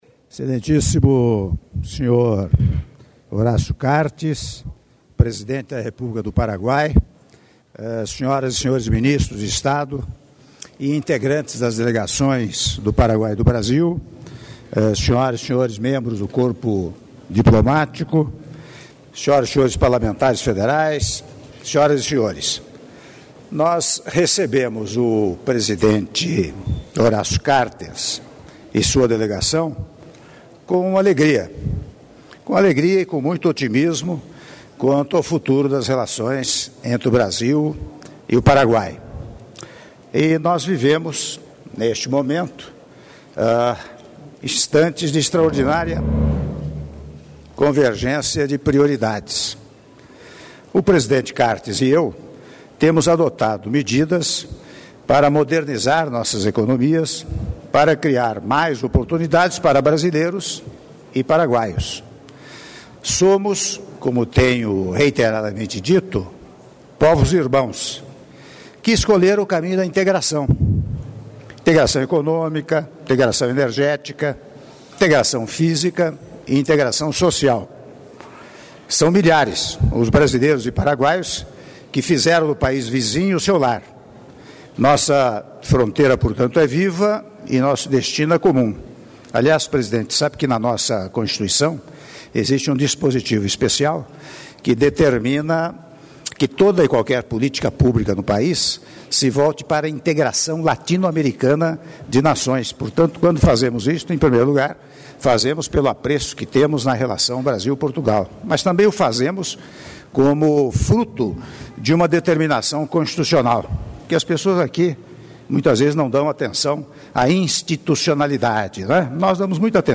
Áudio do discurso do Presidente da República, Michel Temer, durante almoço oferecido ao presidente do Paraguai, Horacio Cartes - Brasília/DF (05min23s)